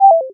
question_002.ogg